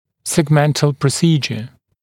[segˈmentl prə’siːʤə][сэгˈмэнтл прэ’си:джэ]сегментарная хирургическая операция